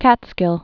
(kătskĭl)